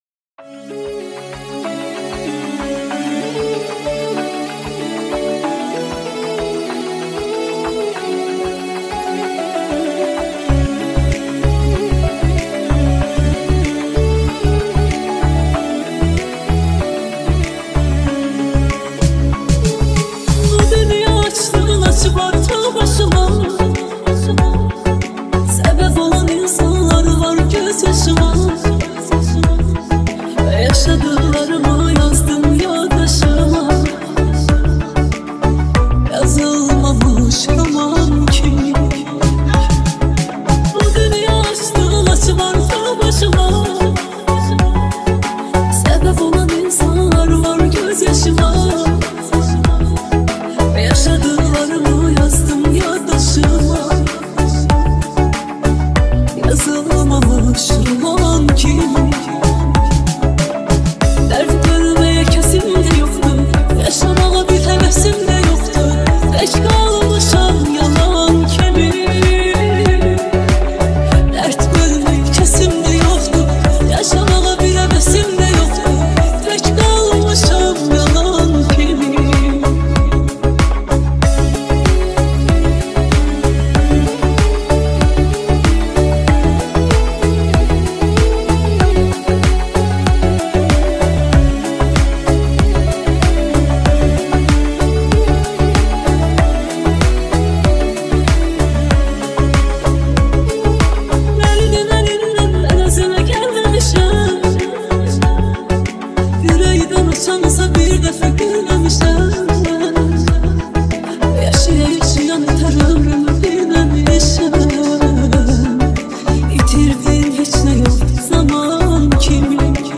آهنگ ترکی فوق‌العاده احساسی